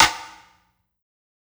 SNARE_MILEY.wav